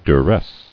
[du·ress]